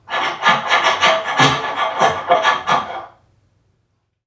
NPC_Creatures_Vocalisations_Robothead [82].wav